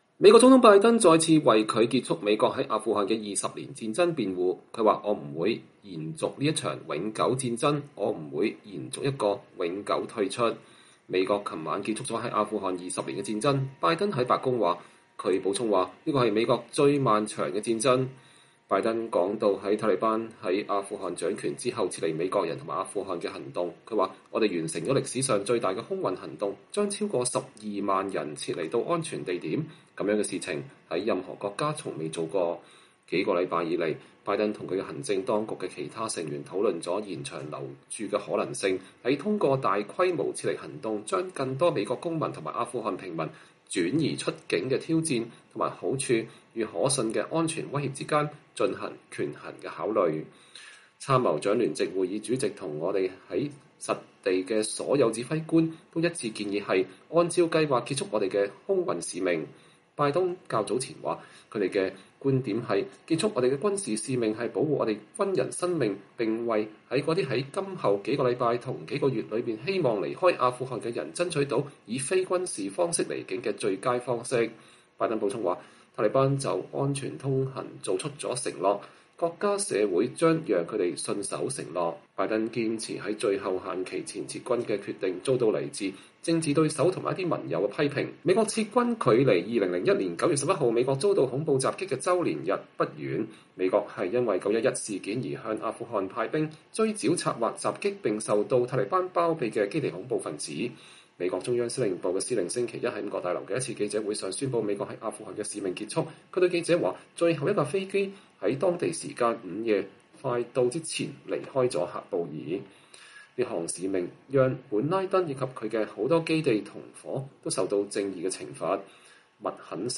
拜登總統在白宮發表講話，談阿富汗戰爭的結束。